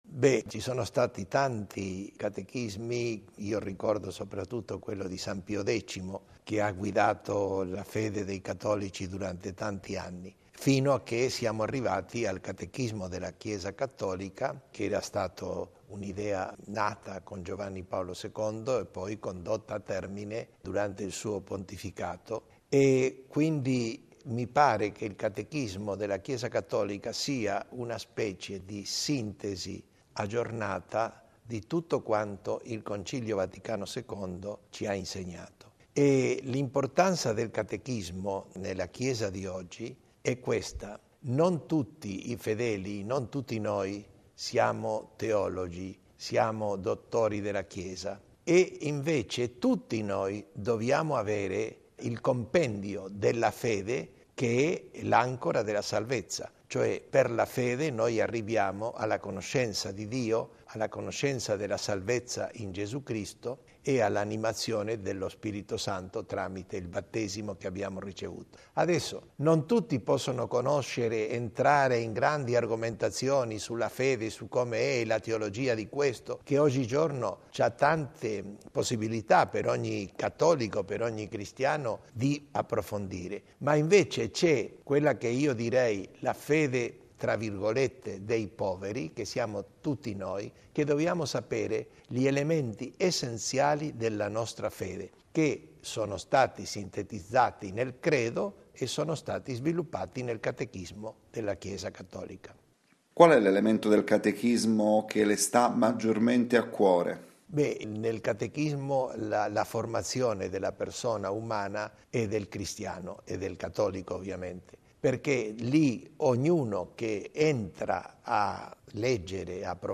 Ascolta l’intervista al cardinale Angelo Bagnasco